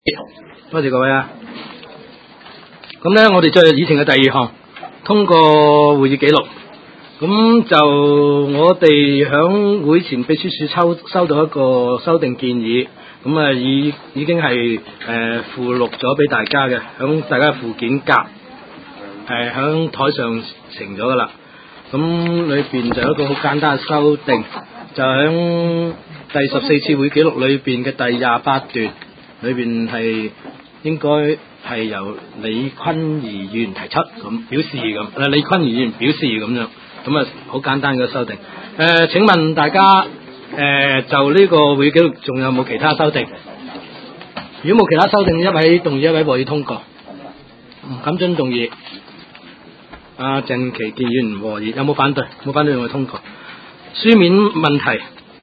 灣仔區議會第十五次會議
灣仔民政事務處區議會會議室